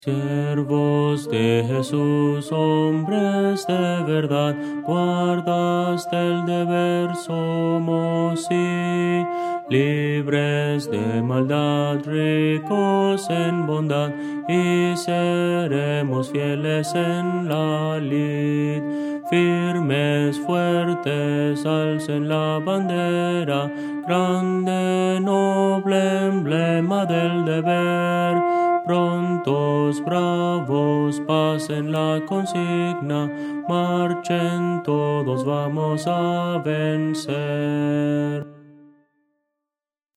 Voces para coro
Contralto – Descargar